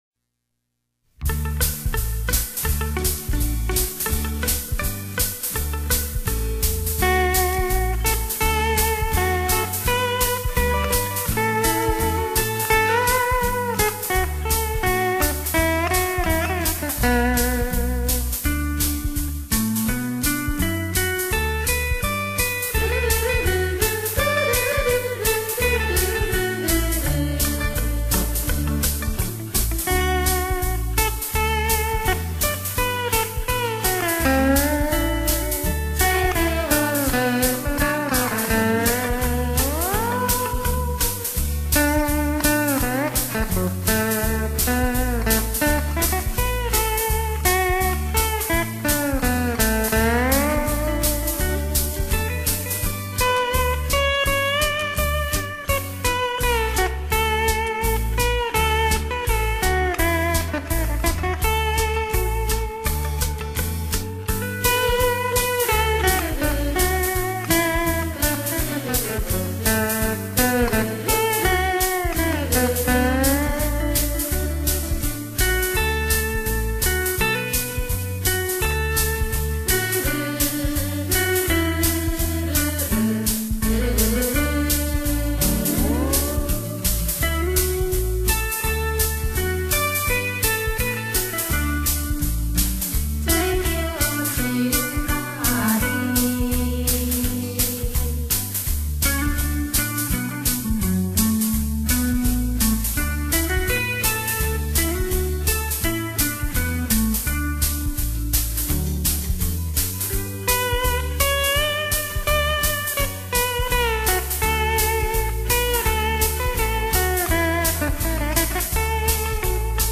优美的琴声，令人心旷神怡的遐想......